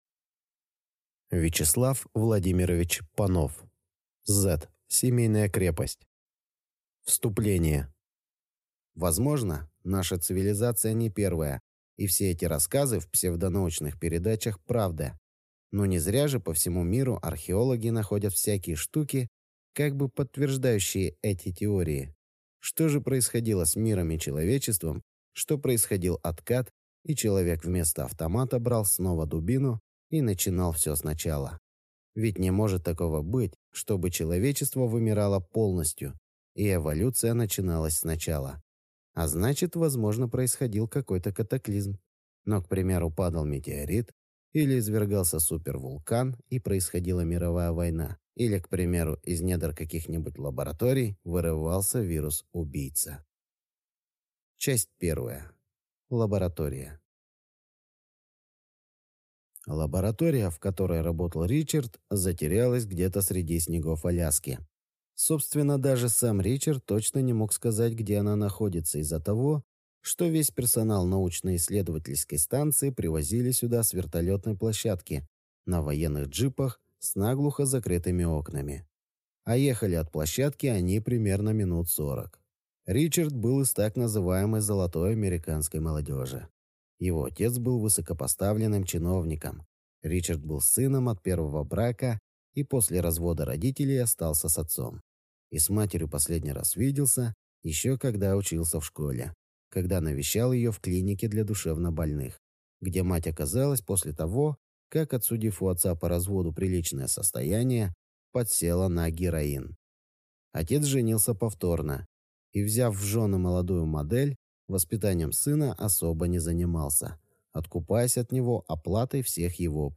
Аудиокнига Z семейная крепость | Библиотека аудиокниг